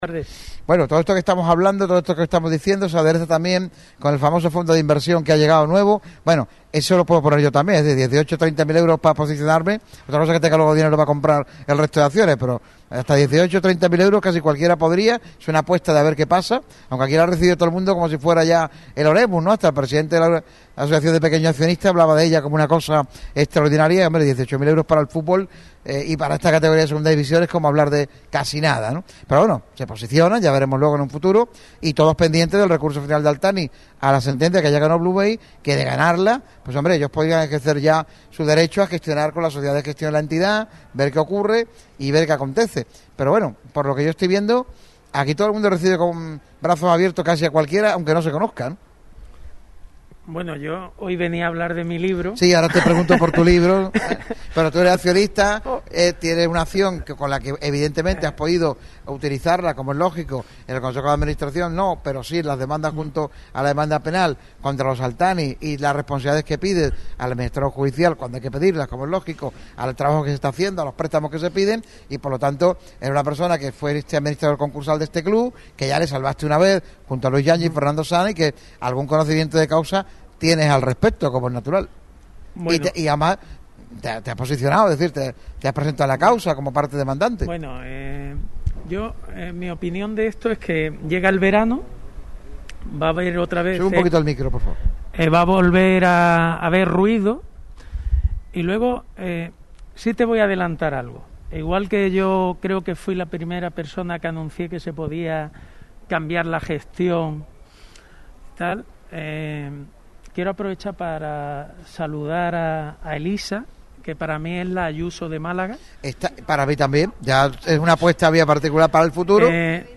Un programa especial celebrado en La Fábrica de Cervezas Victoria.